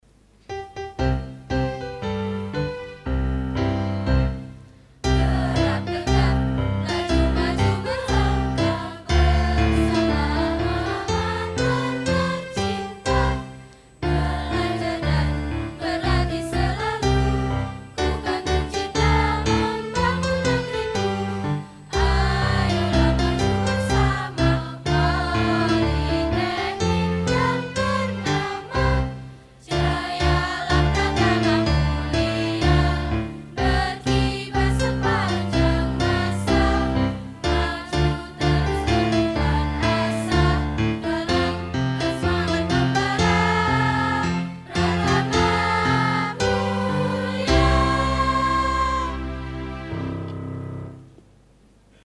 Paduan Suara Mahasiwa Politama